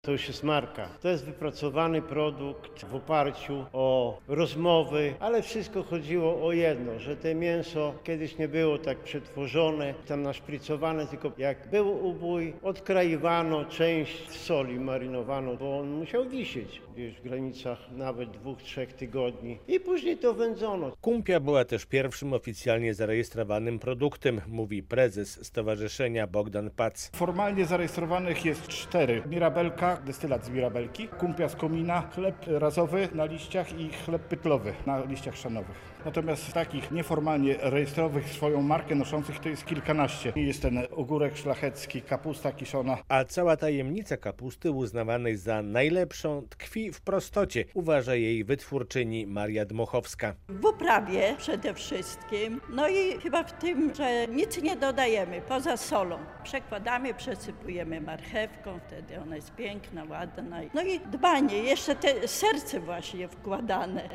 Radio Białystok | Wiadomości | Wiadomości - Zambrowskie Produkty Lokalne.